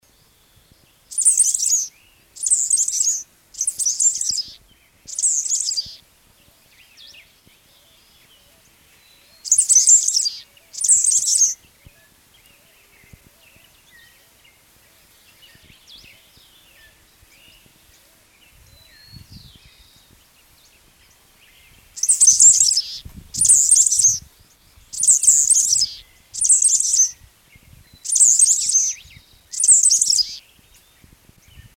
Dull-colored Grassquit (Asemospiza obscura)
Location or protected area: Reserva Natural Parque San Martín, Departamento Paraná
Condition: Wild
Certainty: Recorded vocal